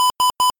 Radar Warning Receiver
ALR69_ThreeBeeps.ogg.mp3